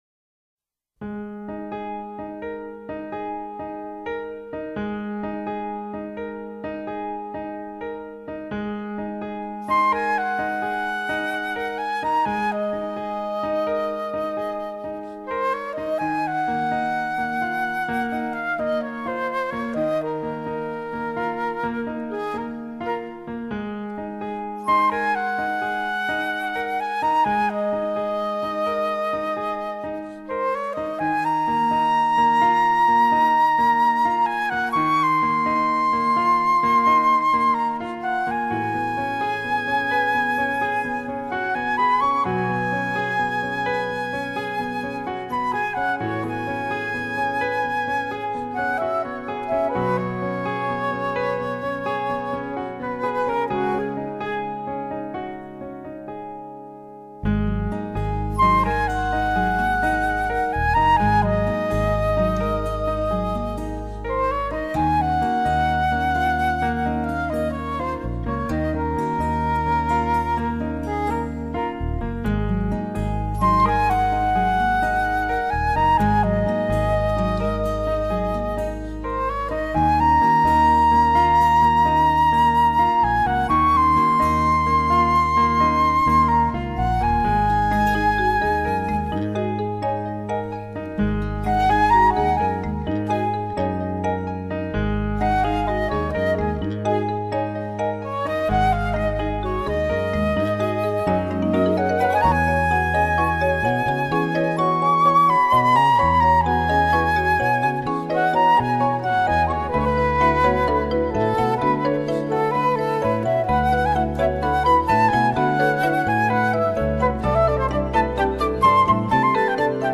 藝術家：純音樂